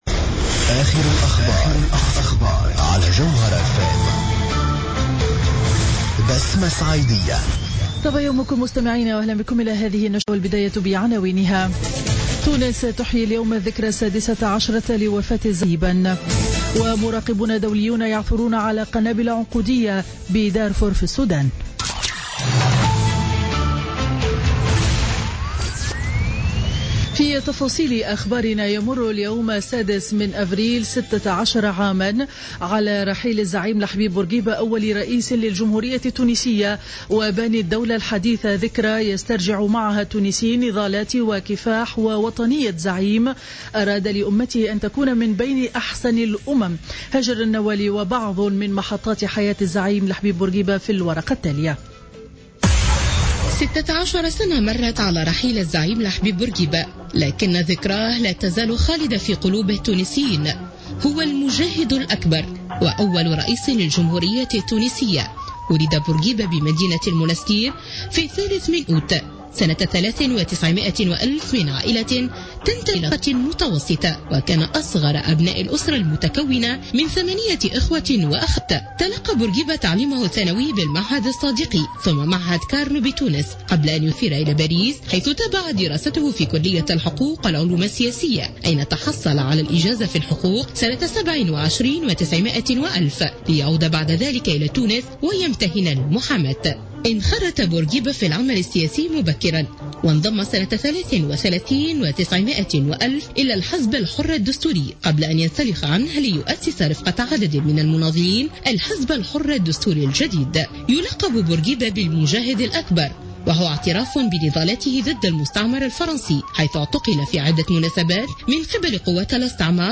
Journal Info 07h00 du mercredi 6 avril 2016